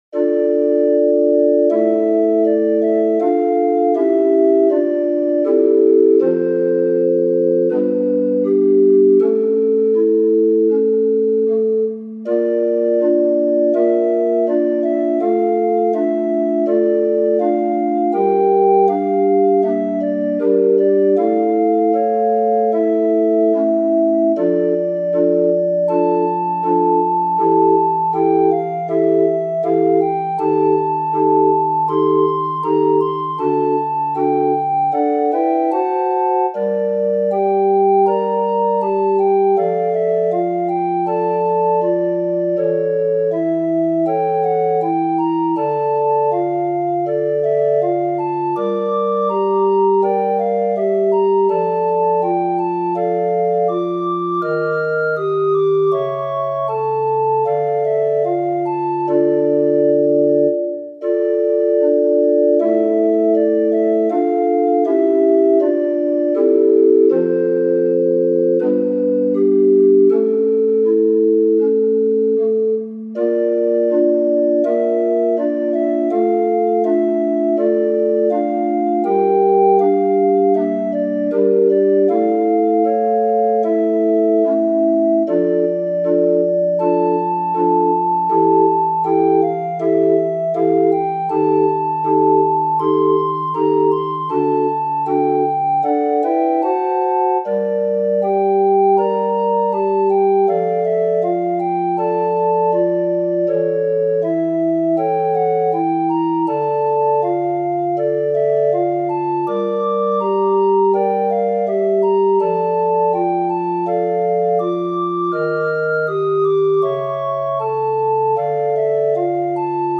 オルガン 厳か